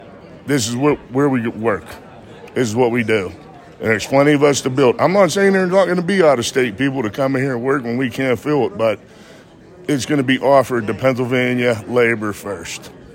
Wednesday night, the Pennsylvania Department of Environmental Protection and Homer City Redevelopment held a public hearing to address the public’s concerns about the proposed air quality treatment plan for the proposed natural gas-fired power plant in Center Township.